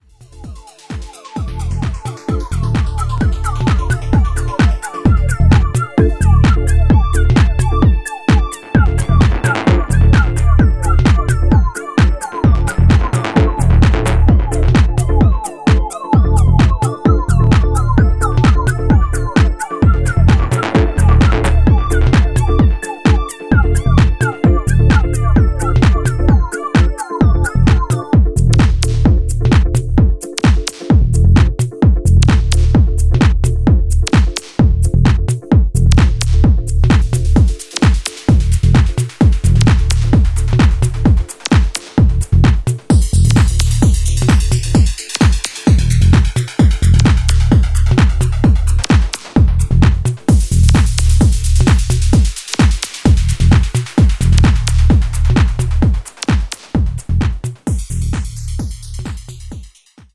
Electro Techno Detroit